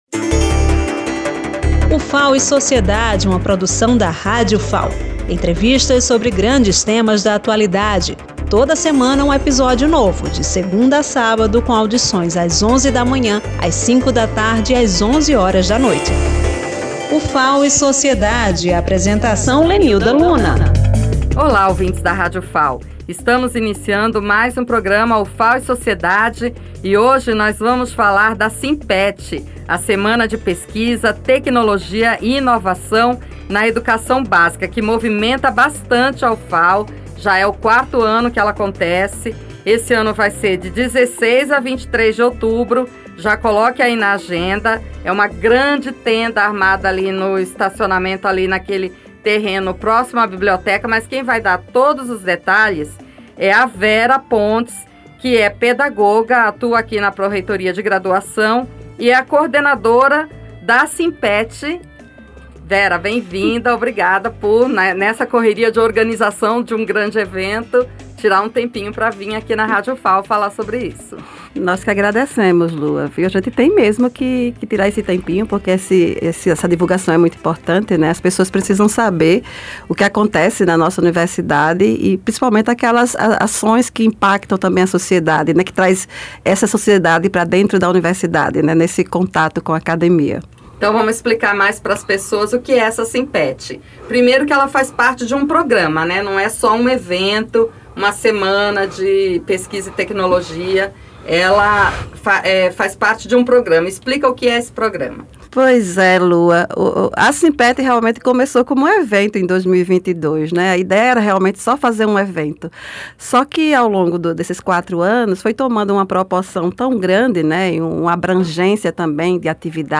Durante a entrevista